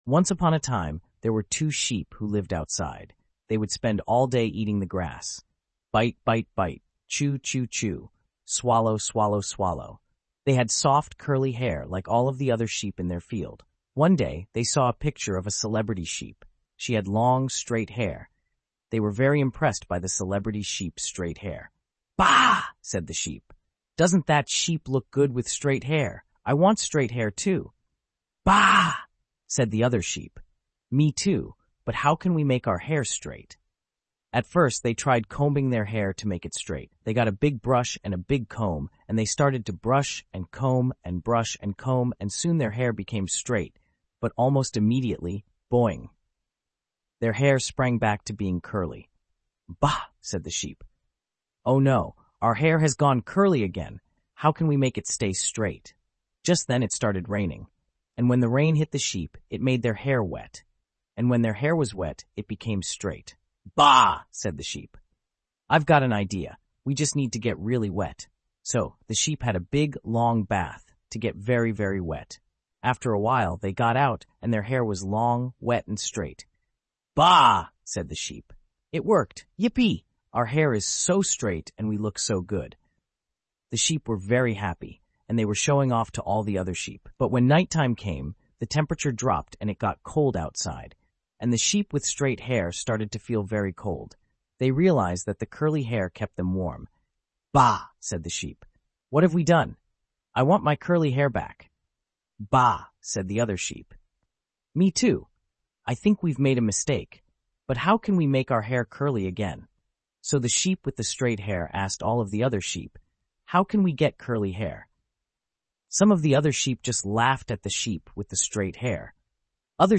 Bedtime Stories